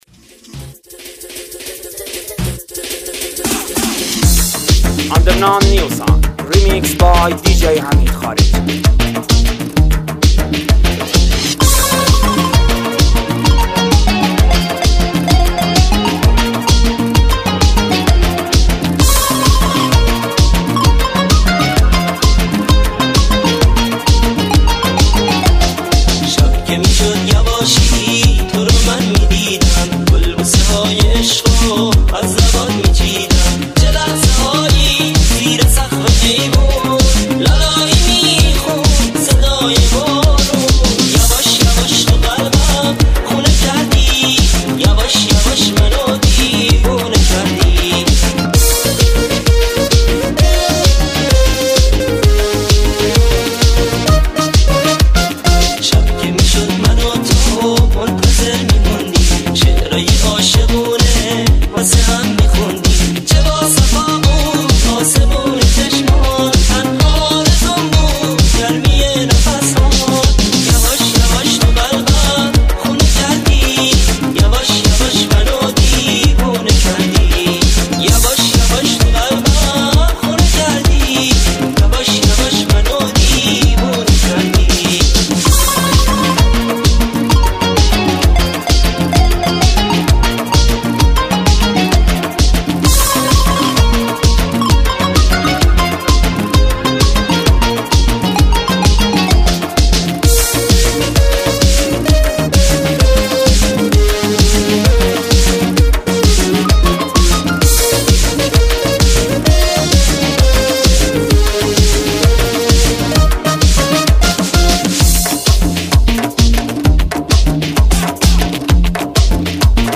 ریمیکس
ریمیکس شاد رقصی